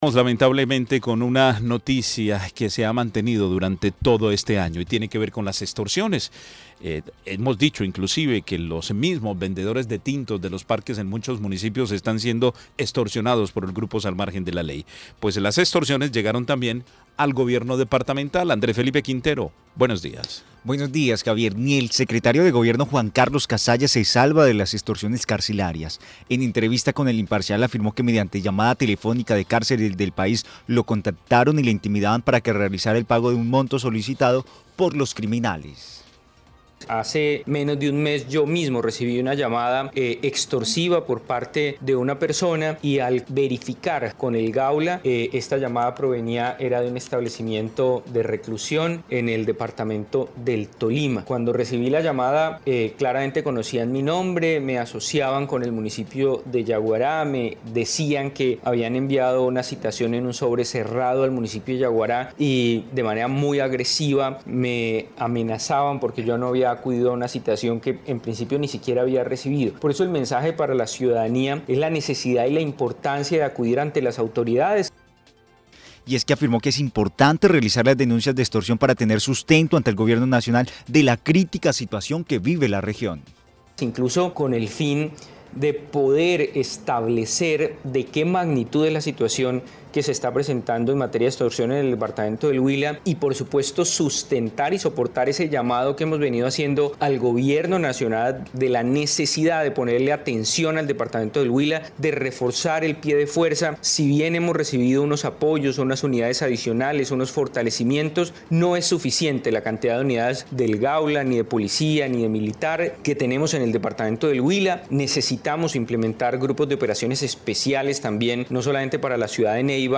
En entrevista, afirmó que mediante llamada telefónica de cárceles del país lo contactaron y le intimidaba para que realizará el pago del monto solicitado por los criminales.